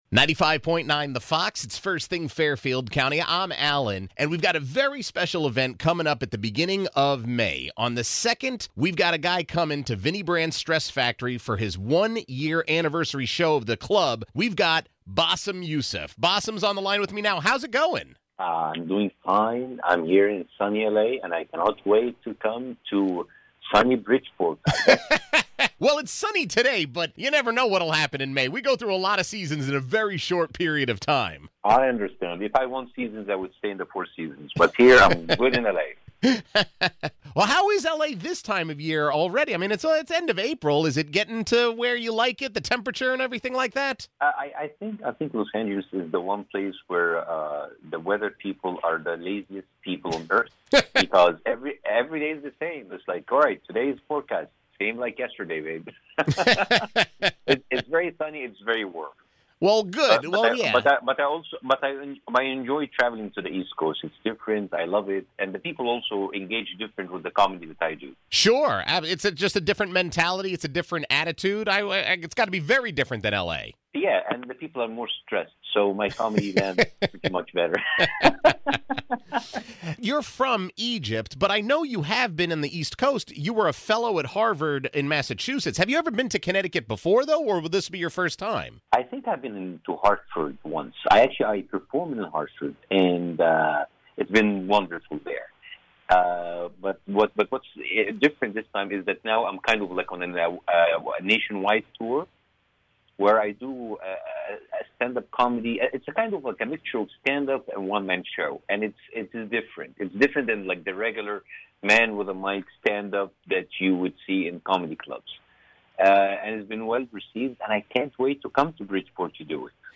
Bassem Youssef stopped by First Thing Fairfield County to talk about it!